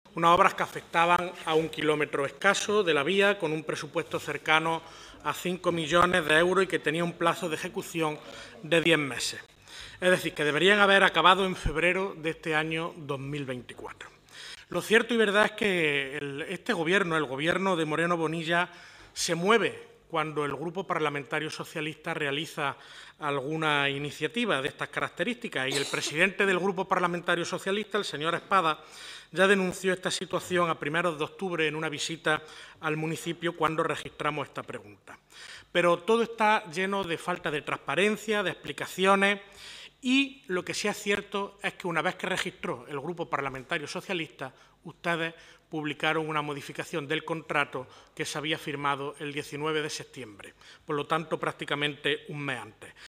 En Comisión de Fomento
Cortes de sonido